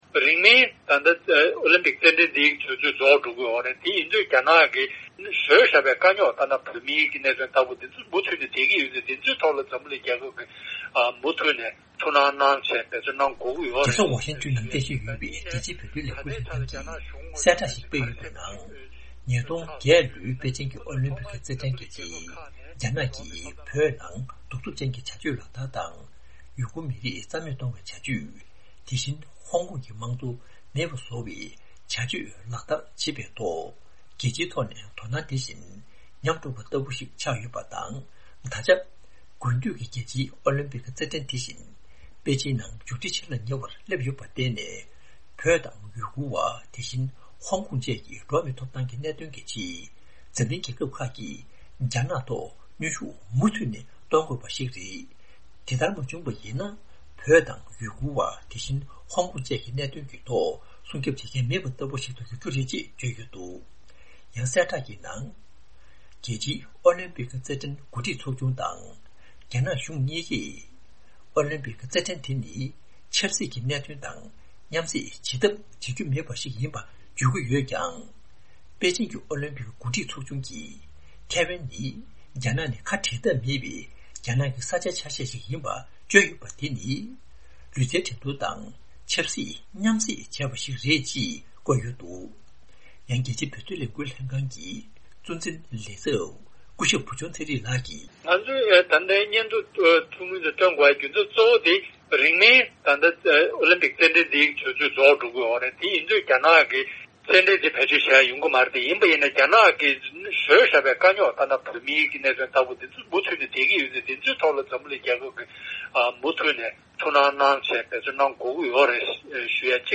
འབྲེལ་ཡོད་མི་སྣར་བཅར་འདྲི་ཞུས་ཏེ་གནས་ཚུལ་ཕྱོགས་བསྒྲིགས་པ་ཞིག་སྙན་སྒྲོན་ཞུ་ཡི་རེད།།